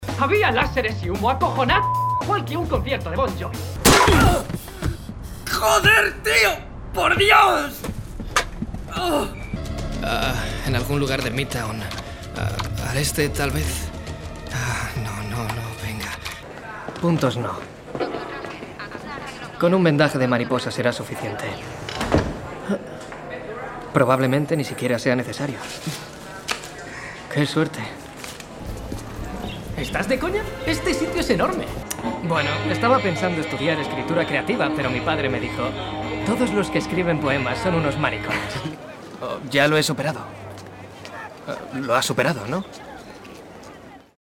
Young professional Spanish voice talent specialised in voice acting and film dubbing. Extremely versatile voice.
kastilisch
Sprechprobe: Sonstiges (Muttersprache):